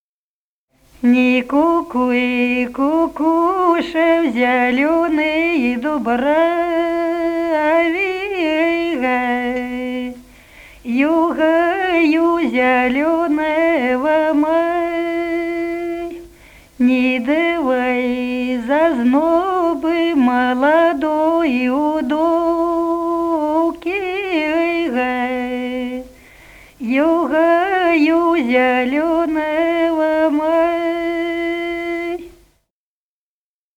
Народные песни Смоленской области
«Не кукуй, кукуша» (майская, троицкая).